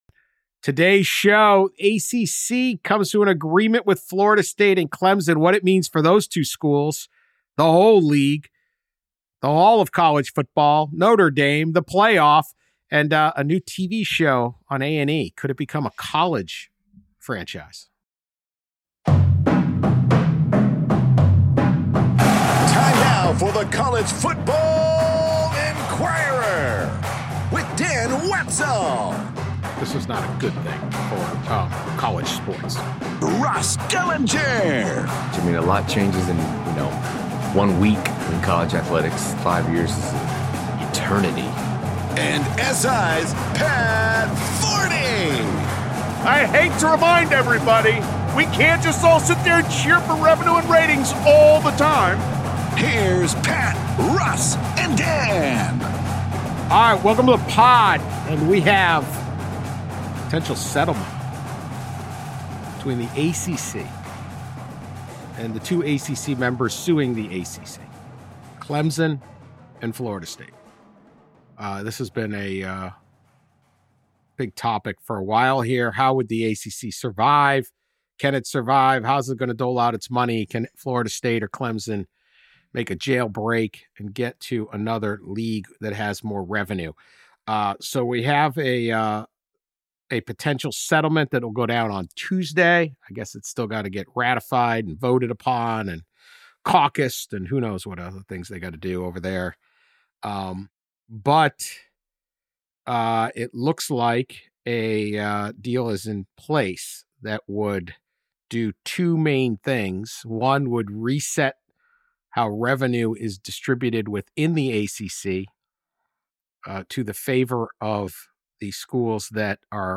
And will other Power conferences look to the ACC’s example and base their own revenue sharing on TV performance as well? The trio also debate which college football stadium would be the best location for a ‘lockup’ TV show.